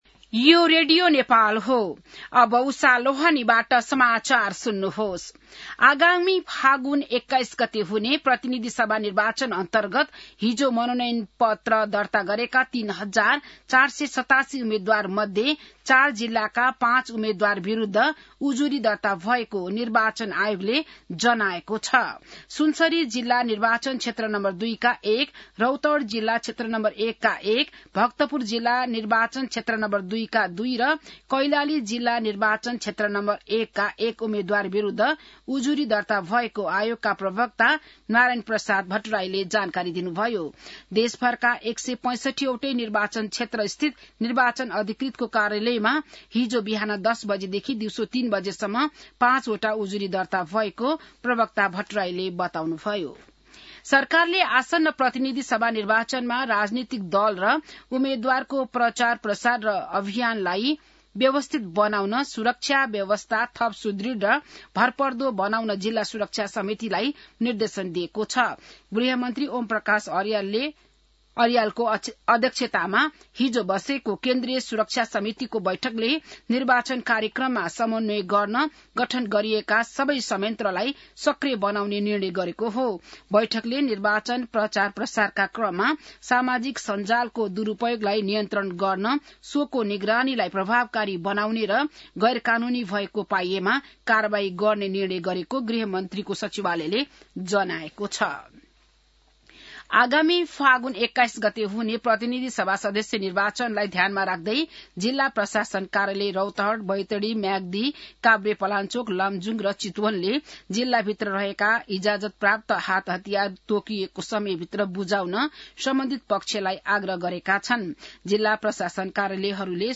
An online outlet of Nepal's national radio broadcaster
बिहान १० बजेको नेपाली समाचार : ८ माघ , २०८२